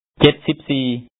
si2p-sa4am 13